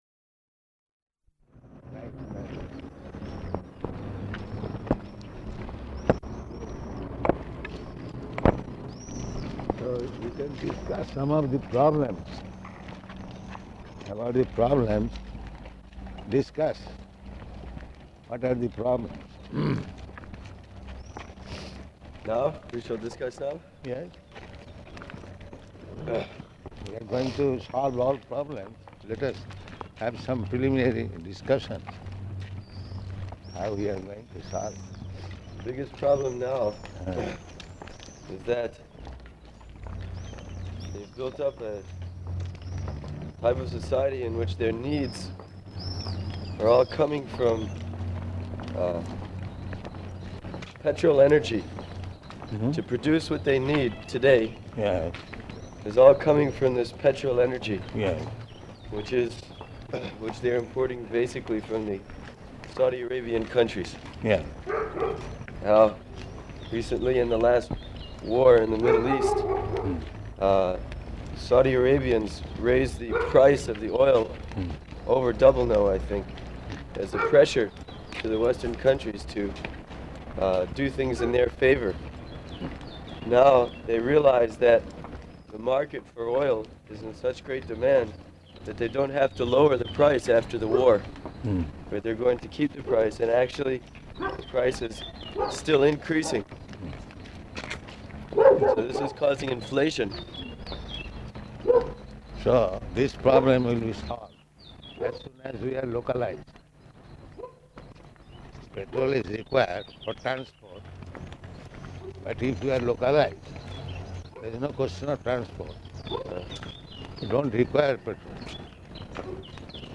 Morning Walk --:-- --:-- Type: Walk Dated: May 27th 1974 Location: Rome Audio file: 740527MW.ROM.mp3 Prabhupāda: So we can discuss some of the problems, about the problems.